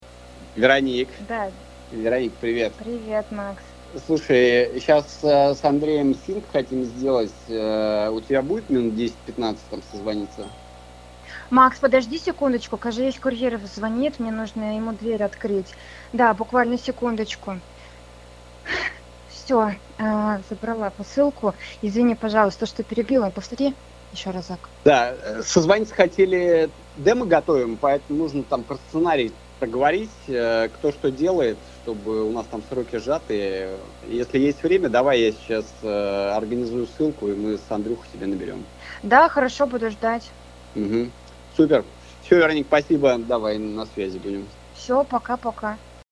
Пример 2: дом + компрессор и собака
Результат с AI TelcoMixer: собеседника слышно без треска на фоне и лая
call-compressor-1.mp3